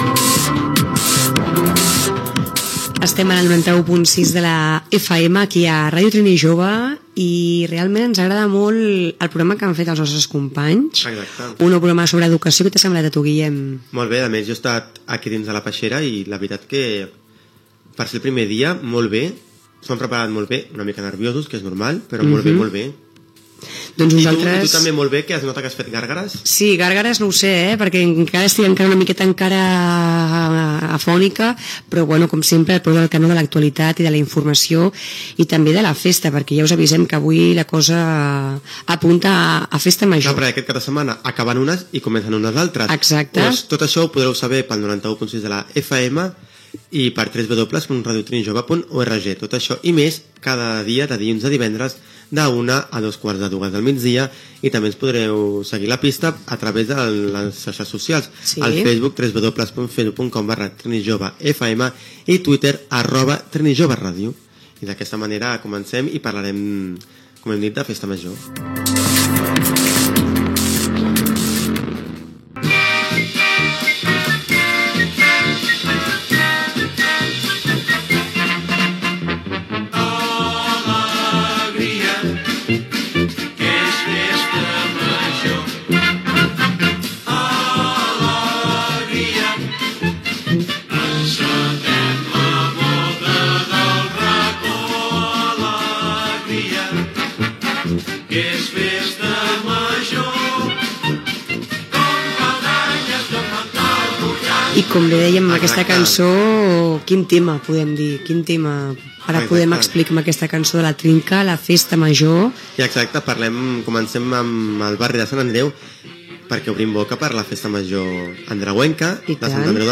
d242b627efa05952fe5eb7e8b7e7831e78d4c00d.mp3 Títol Ràdio Trinijove Emissora Ràdio Trinijove Titularitat Tercer sector Tercer sector Barri o districte Nom programa Informatiu Trinijove Descripció Identificació, formes d'escoltar l'emissora, festes majors de Sant Andreu del Palomar i de La Sagrera (Barcelona). Gènere radiofònic Informatiu